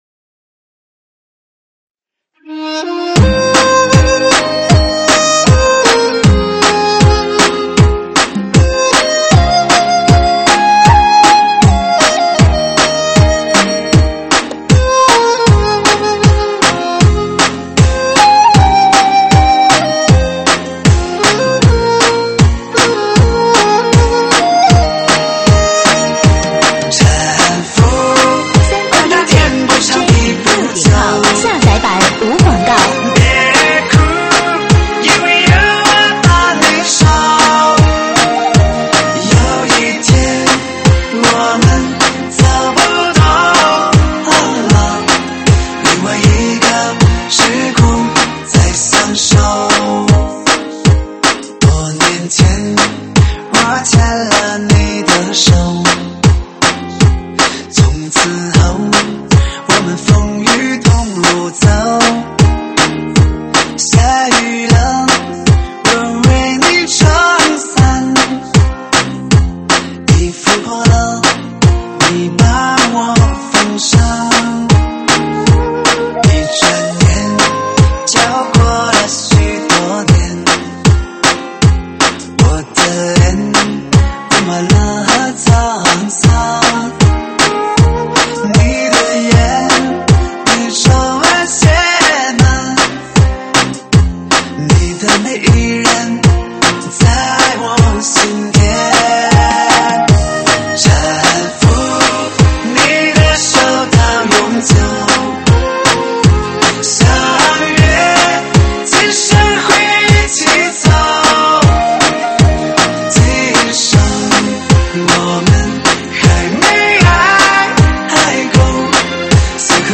舞曲类别：伤感情歌